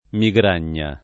migragna [ mi g r # n’n’a ]